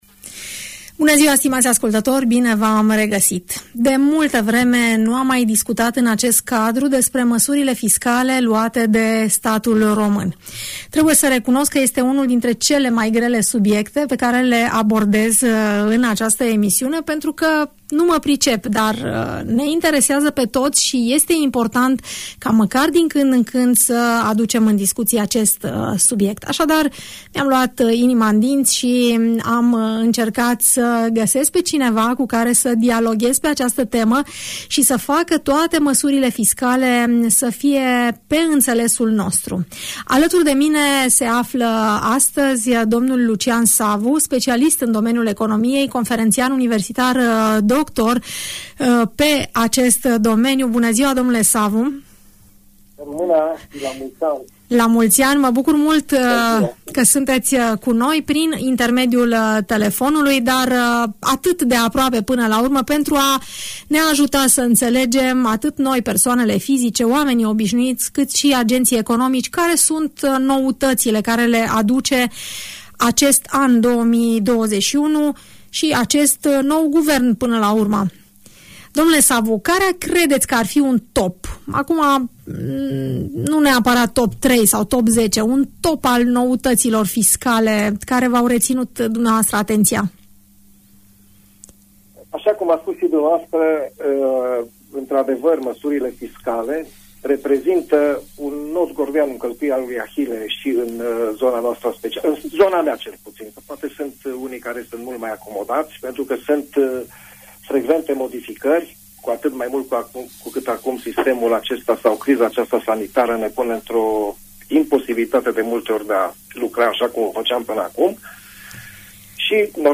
Cele mai importante noutăți fiscale care trebuie aplicate de către persoanele fizice sau juridice în 2021, sunt discutate pe larg în emisiunea „Părerea ta”, difuzată la Radio Tg Mureș.